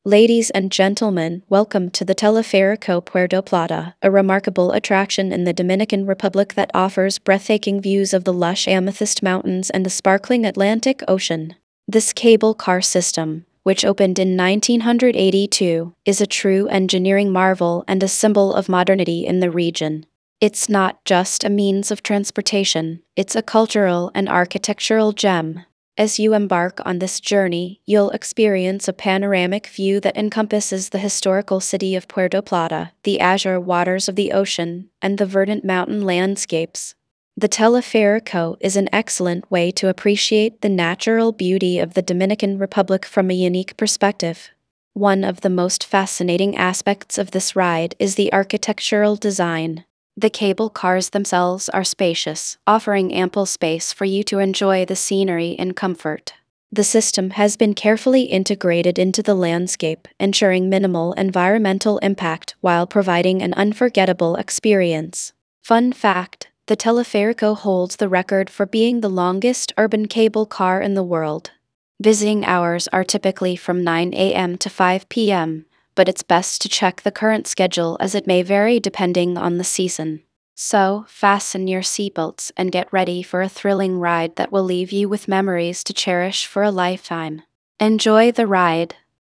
tts